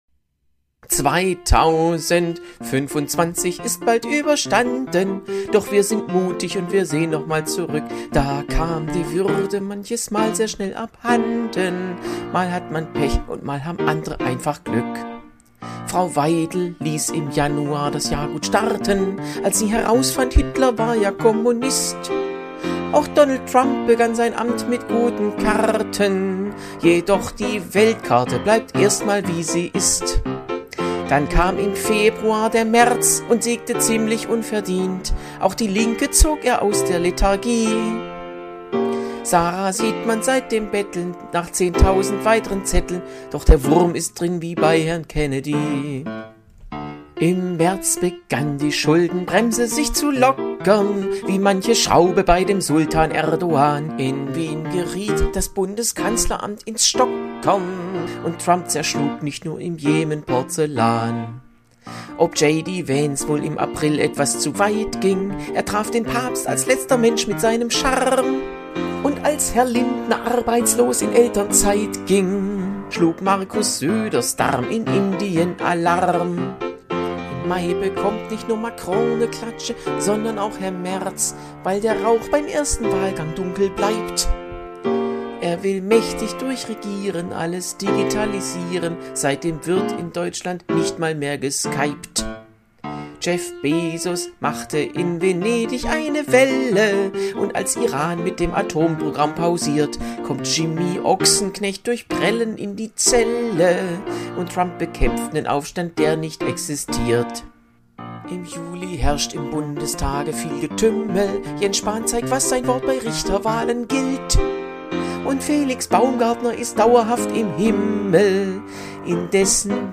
Ein kleiner musikalischer Neujahrsgruß während der Podcastpause: